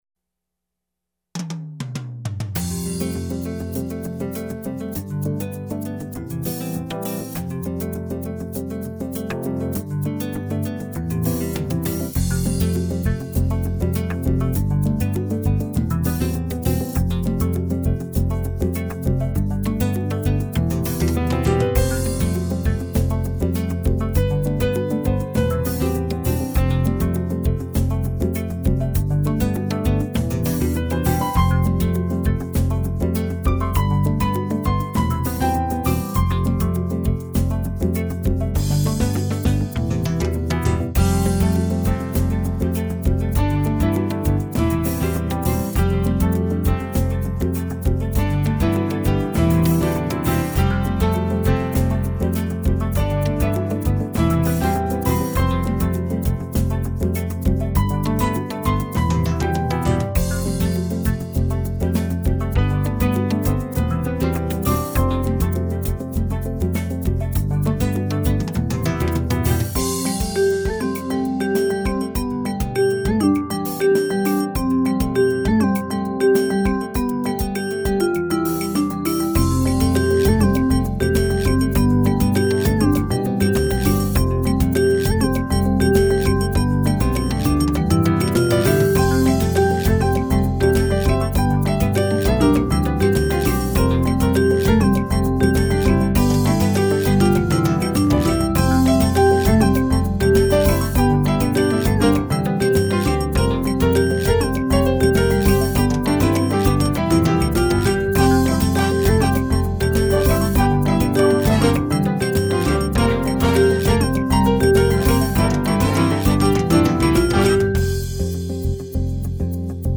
拉丁爵士乐